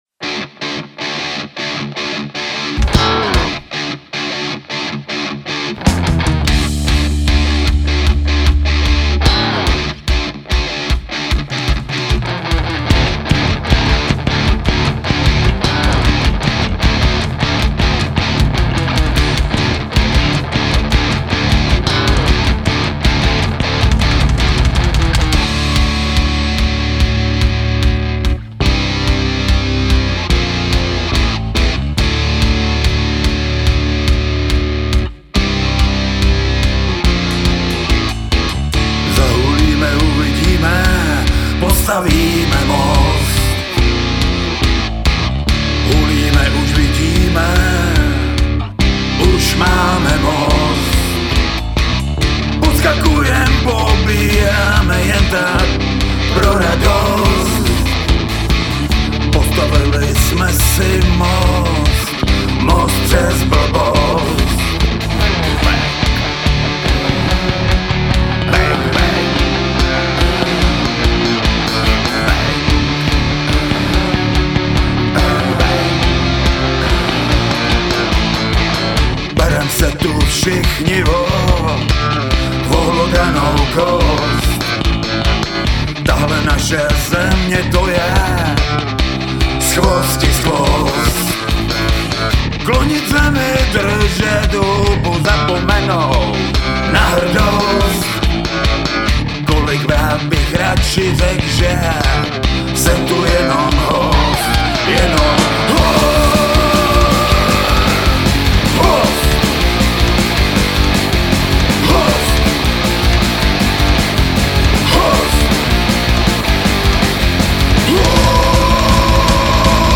Hrál jsem na ně v podleadění do D. wink
Tady jsem na ní i něco nahrával.
To ATK 200 se krásně prosadilo v divoký punkový muzice.